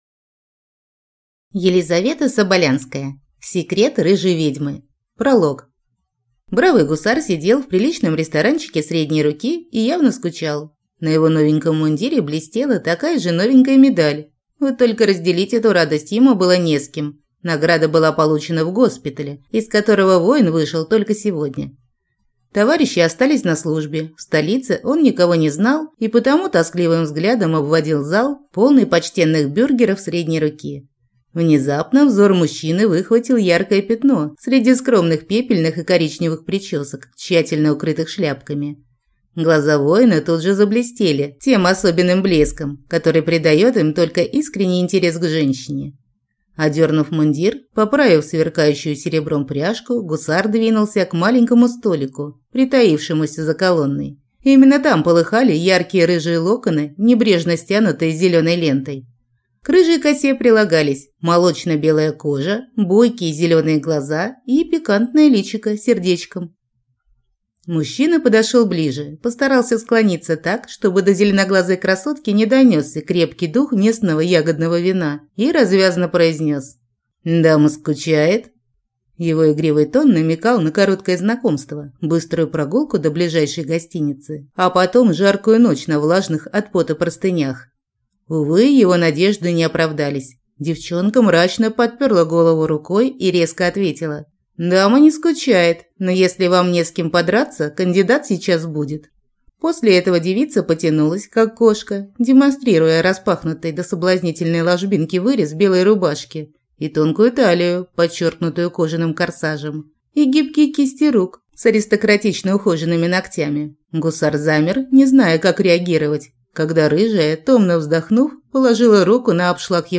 Аудиокнига Секрет рыжей ведьмы | Библиотека аудиокниг